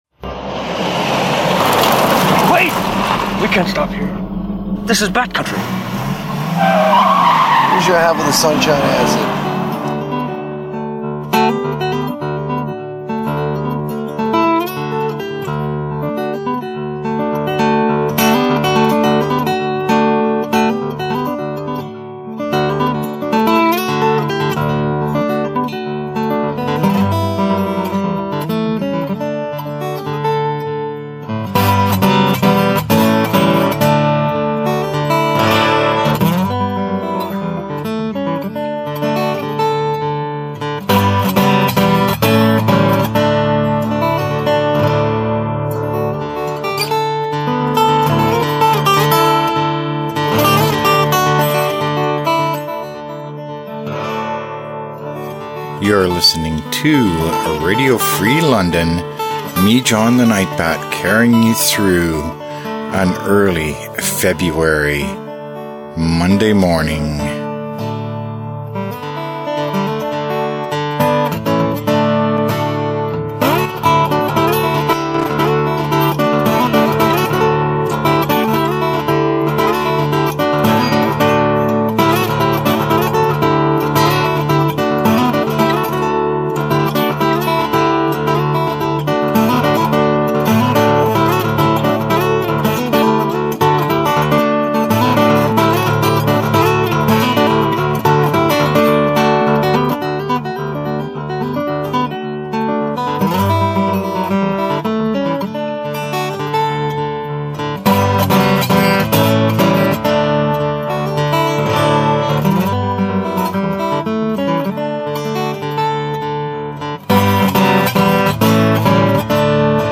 Details: First up, as usual, the Sunday Service from St. James Westminster Anglican Church in Wortley Village.
grand piano
guitar and vocals